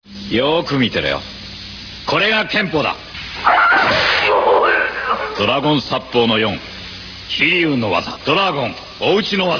リアルプレーヤーにて声優さんの声を聞けるようにしてみました。（９８．４ＵＰ）
柴　俊夫 俳優、代表作？「シルバー仮面」
これを見てわかる事は、ブルース・リーの声が、低い声とイメージされているようである、実際のブルース・リーの声は少し高めの声